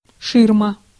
Ääntäminen
IPA: [fa.sad]